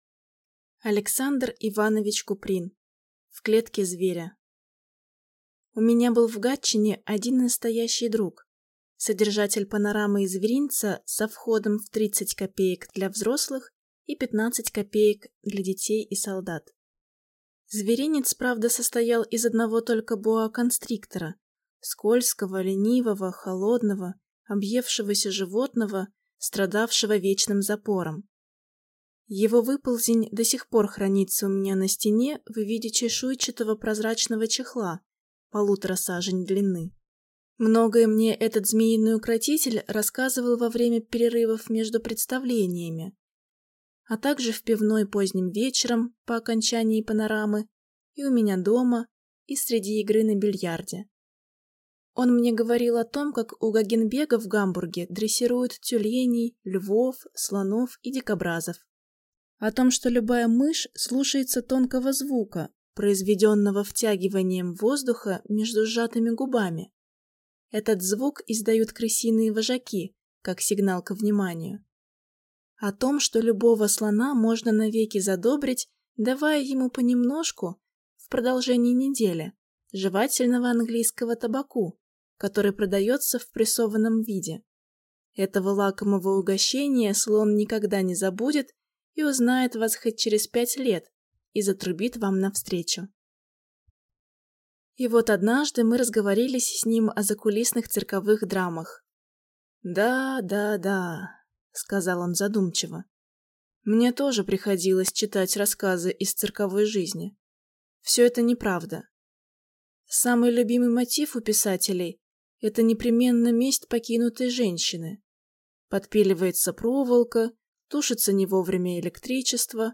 Аудиокнига В клетке зверя | Библиотека аудиокниг